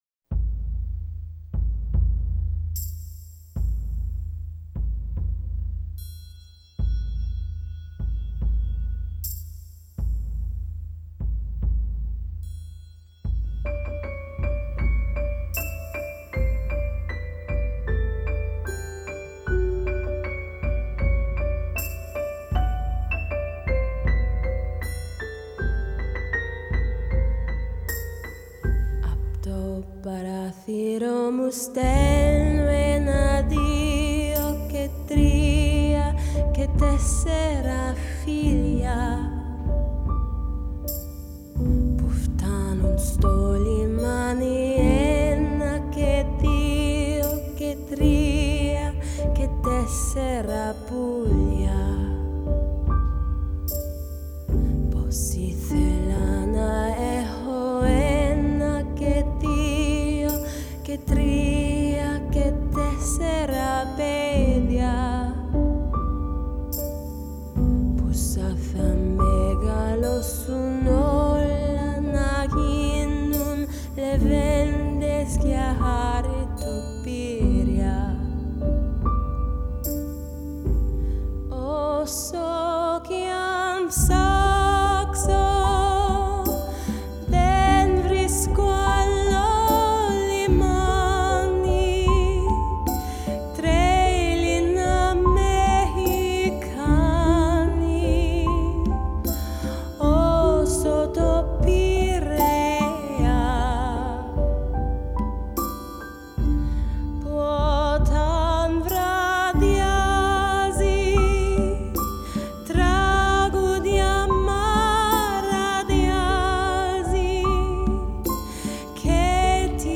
Genre: Jazz
這首曲子只有單純使用擊樂、鋼琴與人聲
虛幻飄渺的剛剛好
Recorded at Stiles Recording Studio in Portland, Oregon.